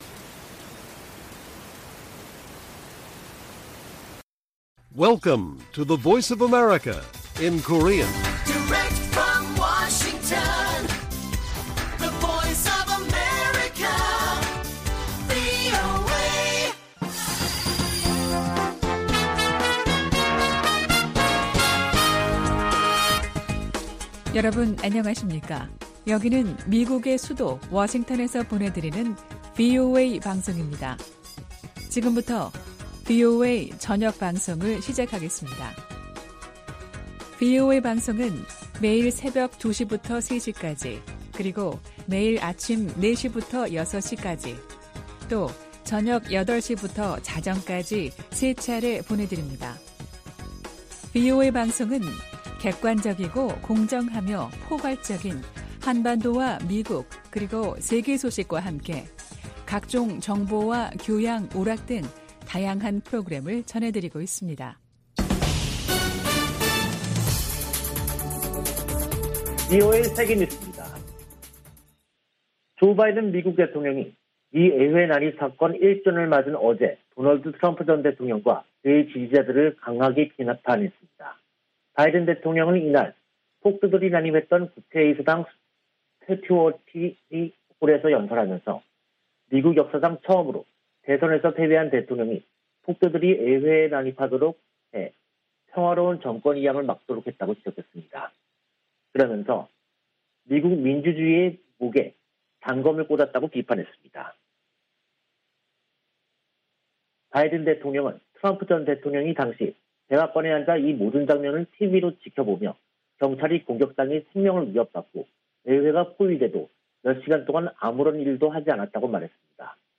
VOA 한국어 간판 뉴스 프로그램 '뉴스 투데이', 2022년 1월 7일 1부 방송입니다. 미국과 일본은 북한의 핵과 미사일 개발에 강한 우려를 표하고 유엔 안보리 결의 준수를 촉구했습니다. 미 국방부는 북한의 미사일 시험발사에 관해 구체적 성격을 평가하고 있다며, 어떤 새로운 능력도 심각하게 받아들인다고 밝혔습니다. 미 국무부는 북한 탄도미사일 관련 안보리 소집 여부에 대해, 동맹과 함께 후속 조치를 논의 중이라고 밝혔습니다.